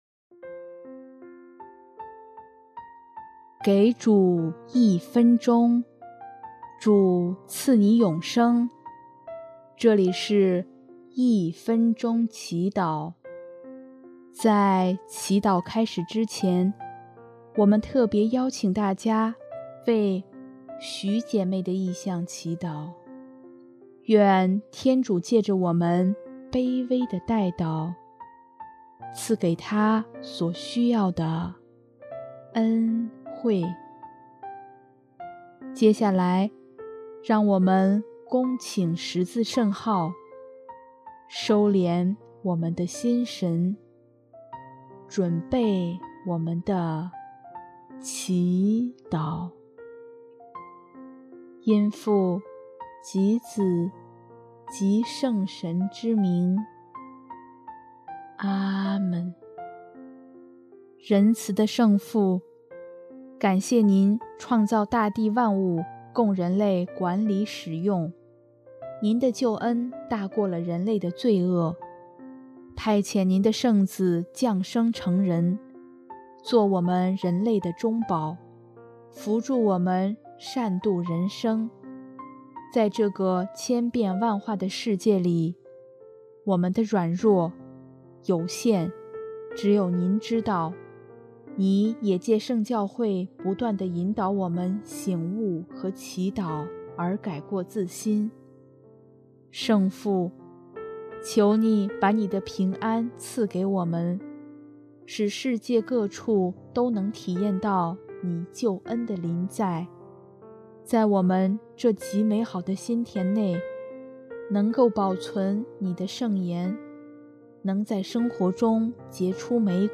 音乐：第四届华语圣歌大赛参赛歌曲《赐我》